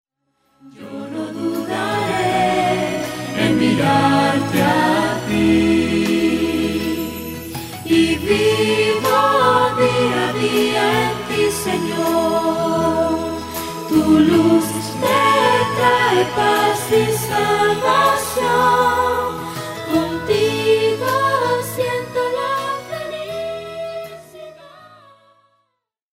llena de adoración y reverencia